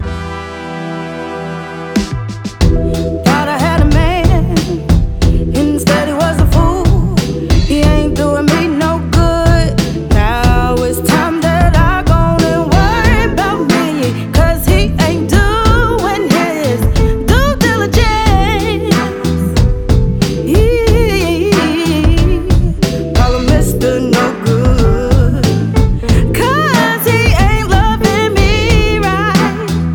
Жанр: Поп музыка / R&B / Соул